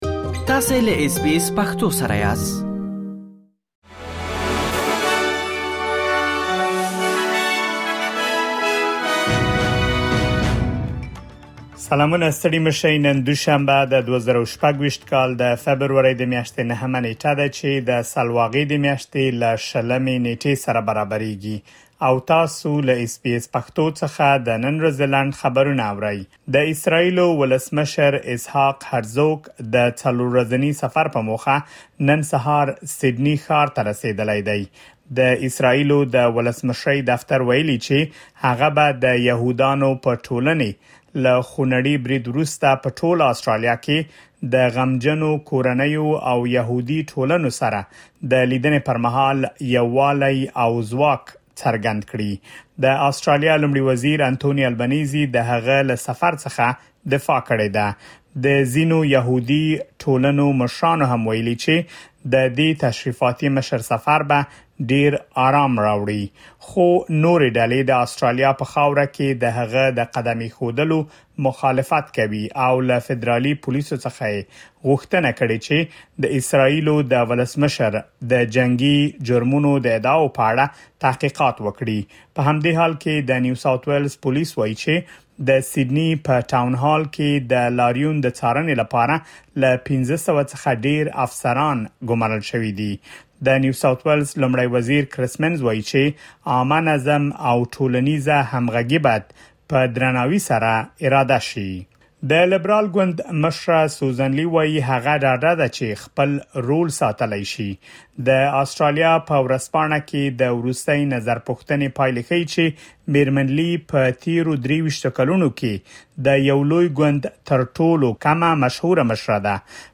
د اس بي اس پښتو د نن ورځې لنډ خبرونه |۹ فبروري ۲۰۲۶